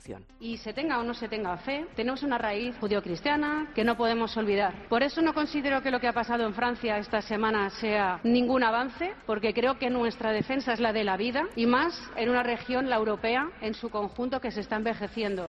Díaz Ayuso, en su intervención en una mesa redonda titulada 'Ganando elecciones' en el marco del Congreso que el PPE ha celebrado en Bucarest (Rumanía), se refería así a lo ocurrido el pasado lunes en el Parlamento francés en una sesión conjunta de ambas cámaras --la Asamblea Nacional y el Senado-- que dieron el visto bueno a incluir el derecho al aborto en su Constitución, convirtiendo a Francia en el primer país del mundo en dar este paso.